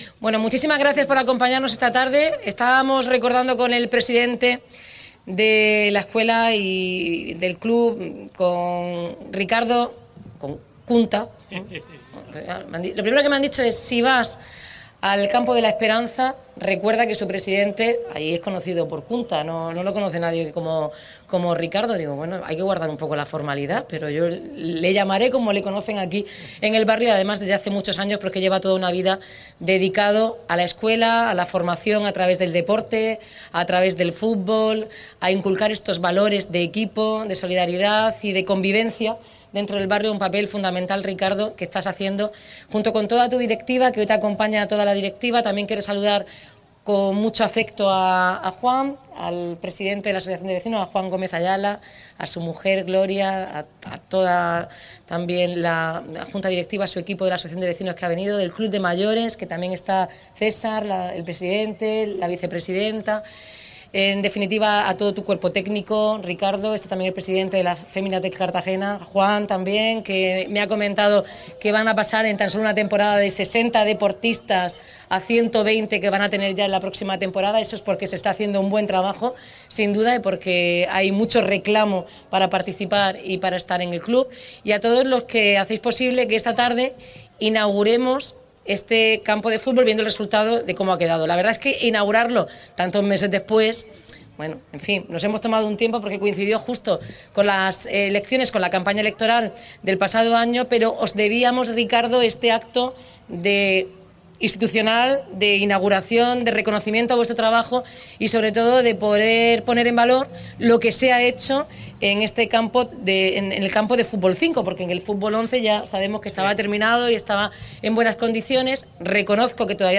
Audio: Inauguraci�n del campo de futbol de la EF La Esperanza (MP3 - 13,17 MB)